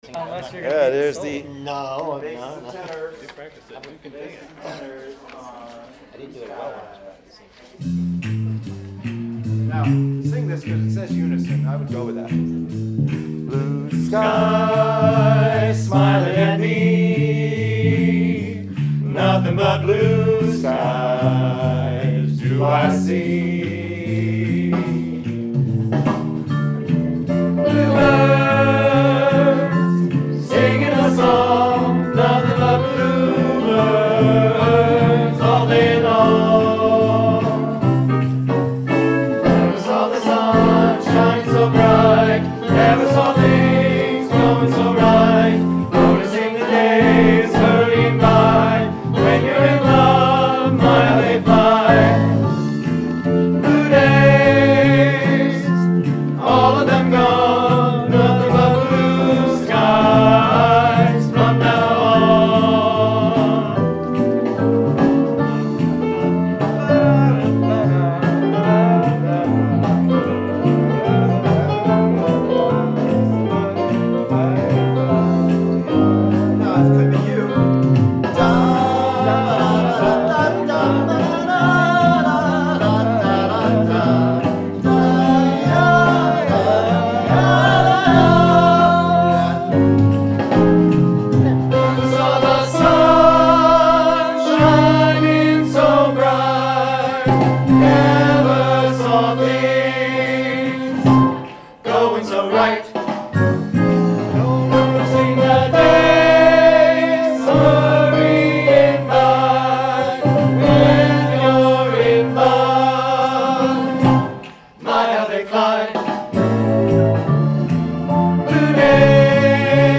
Southbury/Middlebury Community Chorus The temporary home for practice materials Best viewed on a computer - the mobile app is terrible!
A Million Dreams - Soprano A Million Dreams - Alto A Million Dreams - Men Blue Skies Â - Alto Blue Skies - Tenor/Bass Â Website Designed at Homestead™ Make a Website and List Your Business